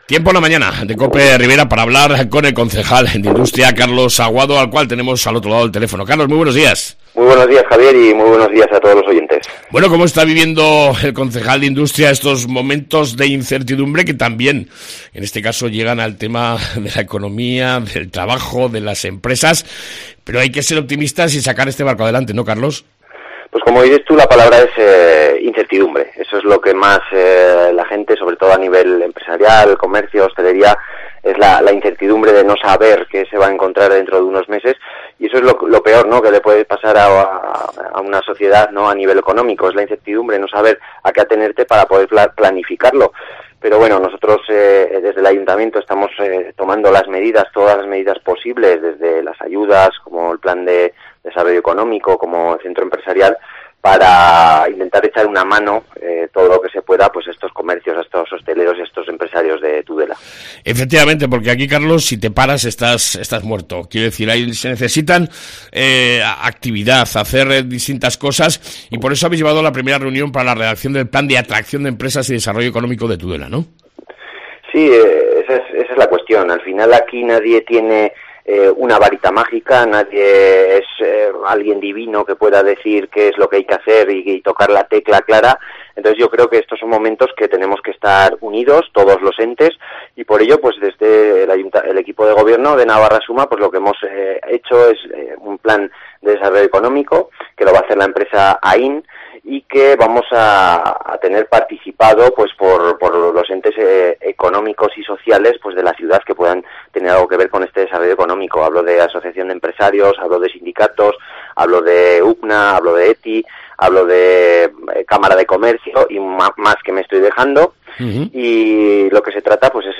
Entrevista con el concejal de Industria Carlos Aguado